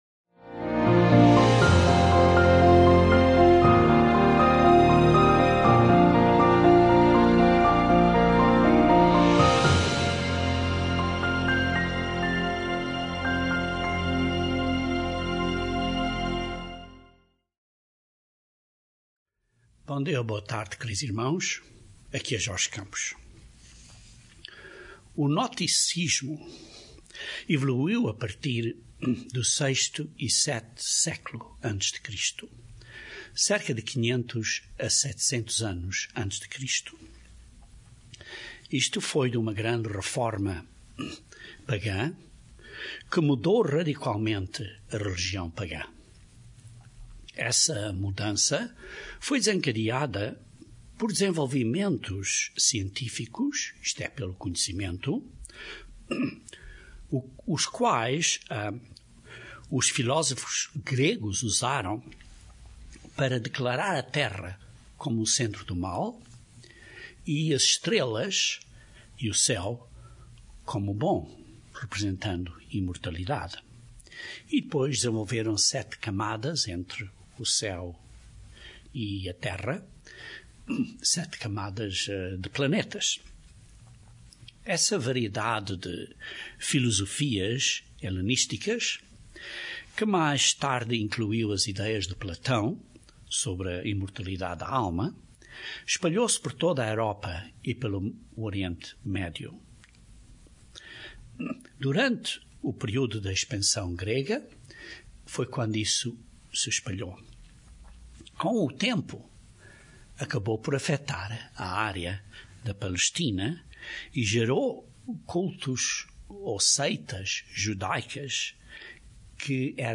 Este sermão descreve várias escrituras na Bíblia que nos avisam contra a heresia do Gnosticismo. Esta doutrina do desejo de sabedoria e de mistérios de conhecimento --- é muito semelhante ao que está acontecendo hoje em dia atravéz de redes socias e teorias de conspiracias.